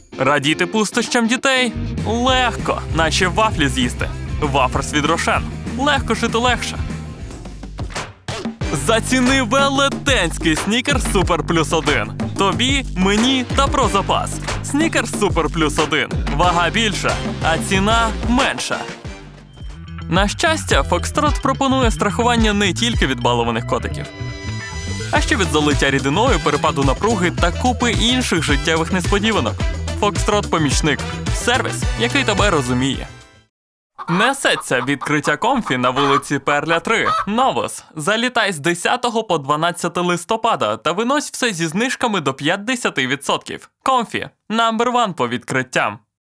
Comercial, Natural, Versátil, Amable, Travieso
Comercial
Known for his reliable, friendly, conversational and playful voice.